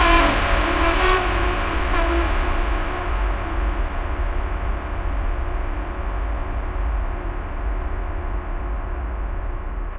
This sound effect was generated by an AI. It has a unique frequency that changes over time.